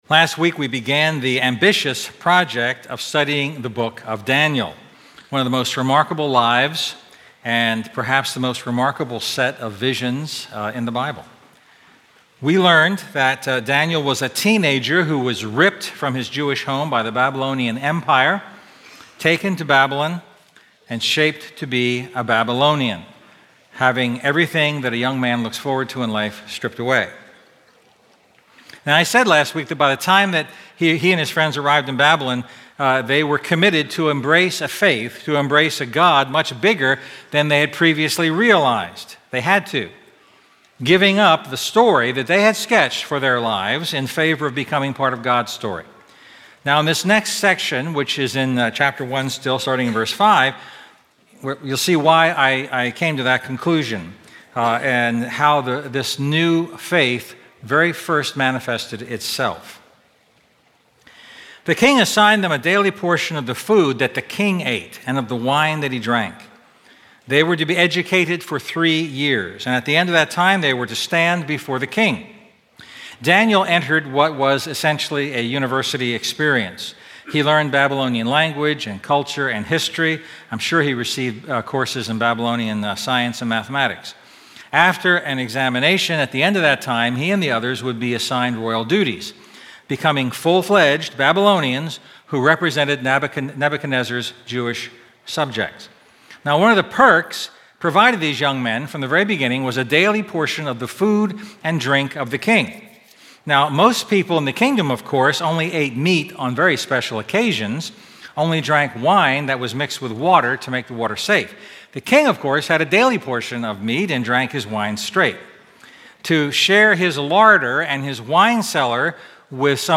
A message from the series "A Larger Faith."